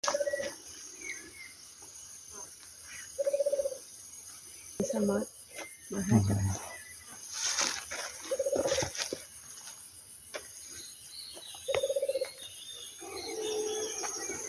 Yeruvá (Baryphthengus ruficapillus)
Nombre en inglés: Rufous-capped Motmot
Localidad o área protegida: Reserva Privada San Sebastián de la Selva
Condición: Silvestre
Certeza: Vocalización Grabada